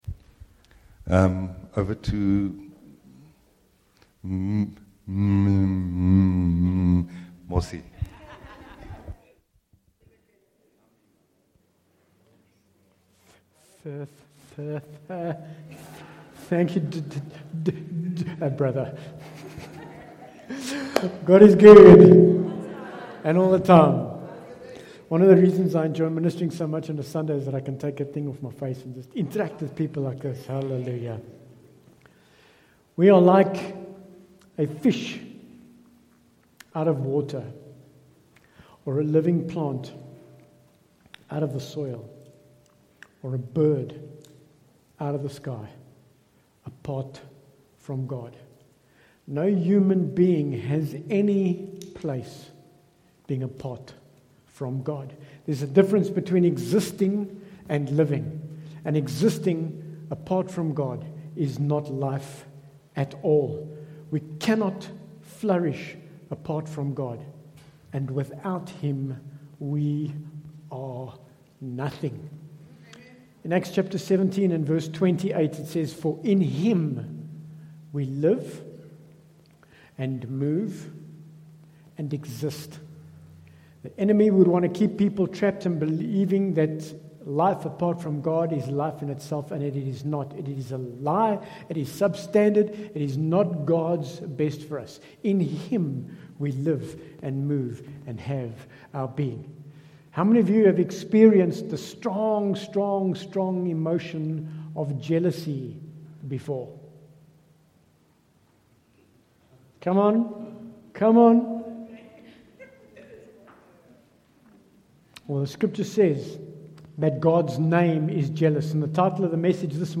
Bible Text: Acts 17:28 | Preacher